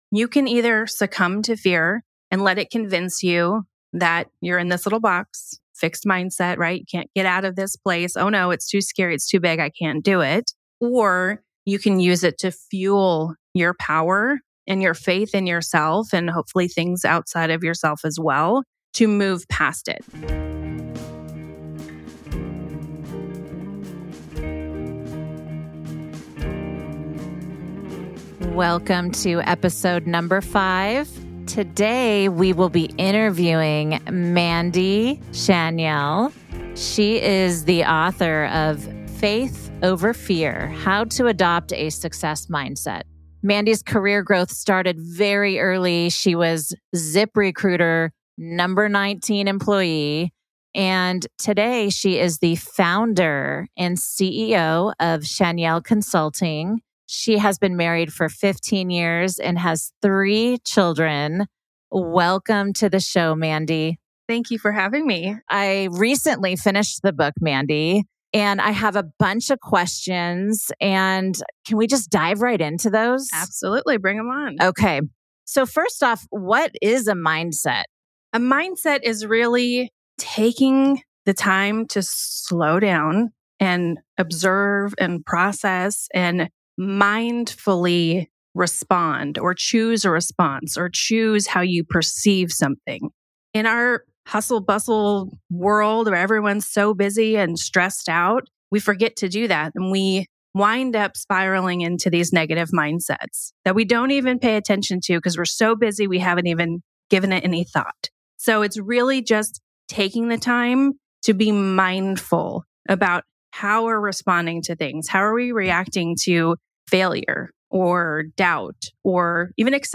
Our candid discussion goes on to cover the unexpected joys of meditation and the pivotal role of emotional intelligence in leadership and personal growth.